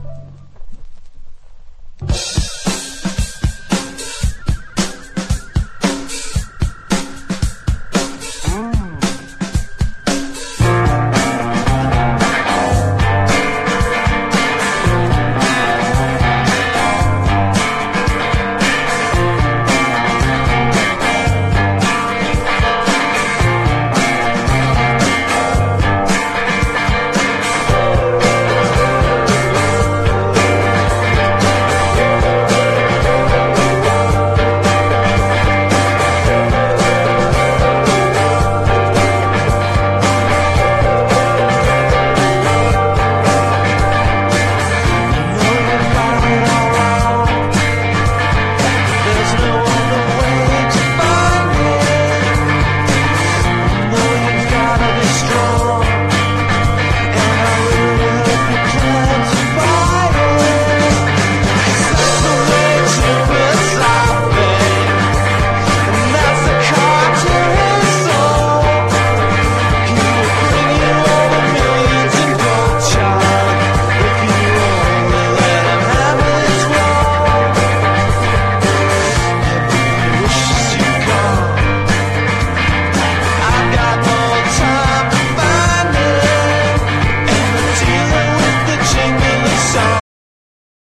バンドの成熟を感じさせる凝ったアレンジとアンサンブルが印象的な97年の4th！